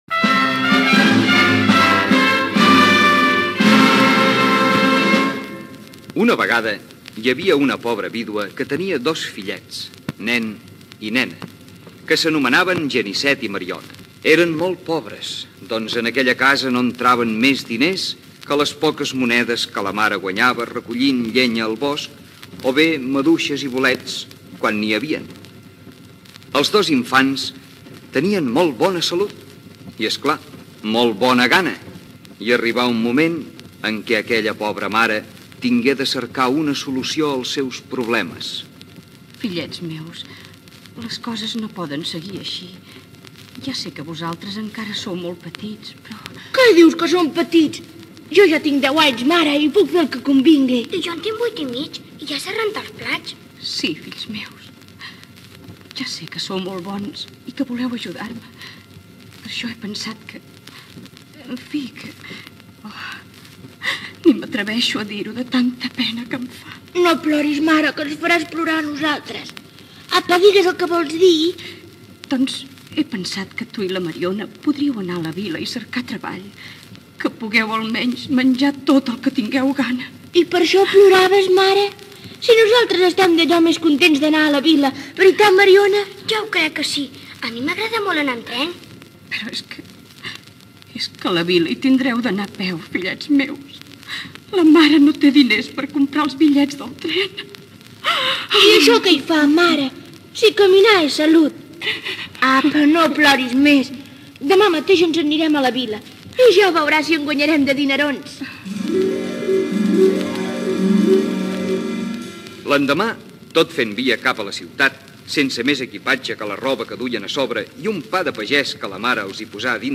Inici de l'adaptació radiofònica del conte infantil "Els àngels confiters" de Florència Grau.
Ficció